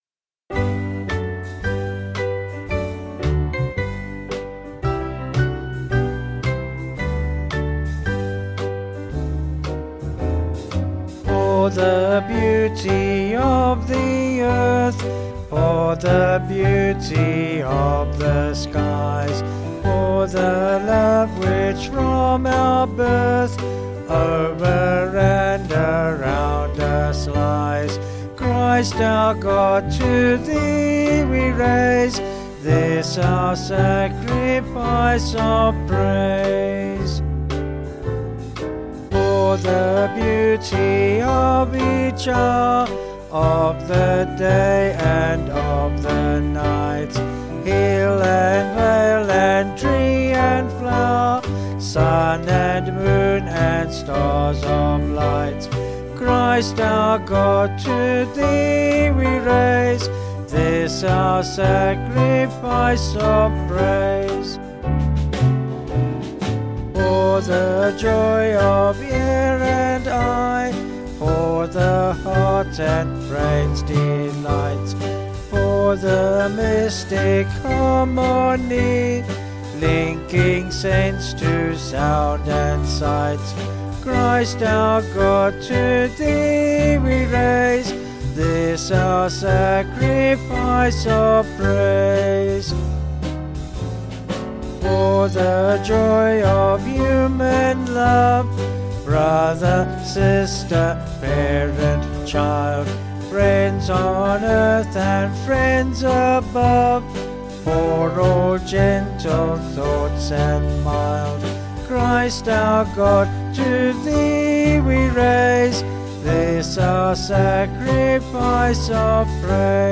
Vocals and Band   263.5kb Sung Lyrics